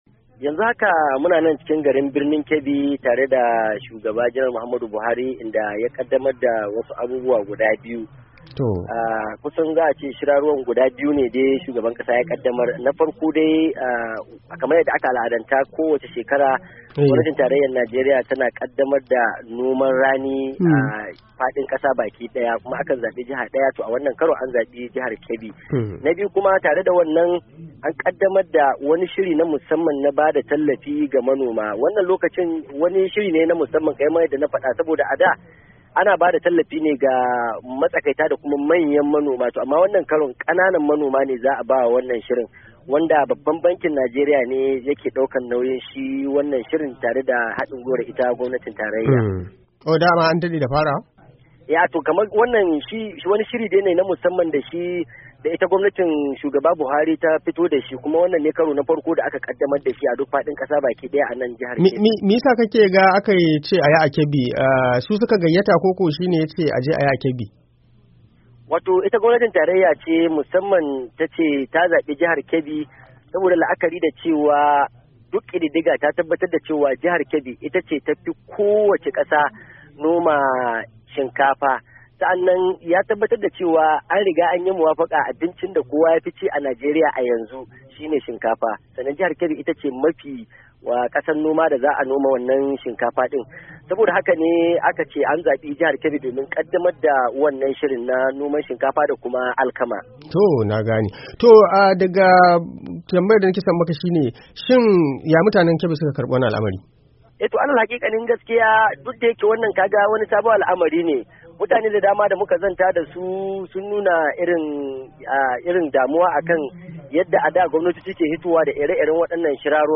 cikakken rahoton